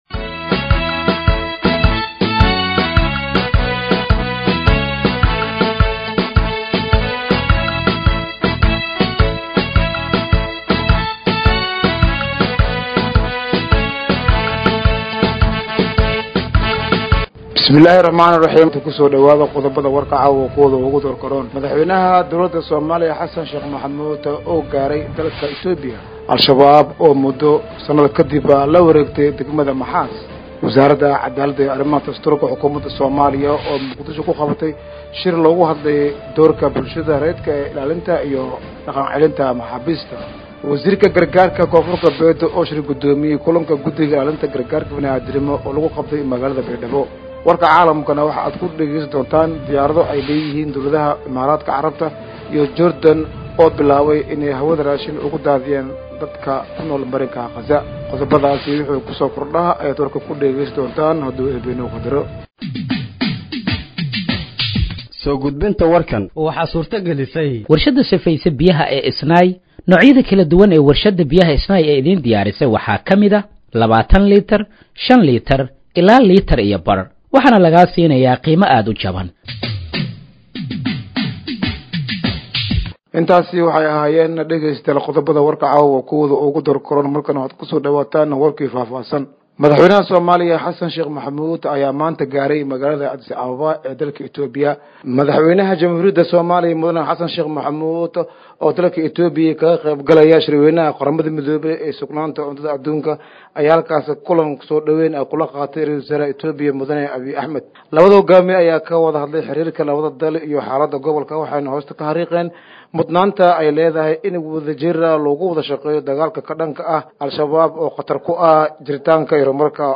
Dhageeyso Warka Habeenimo ee Radiojowhar 27/07/2025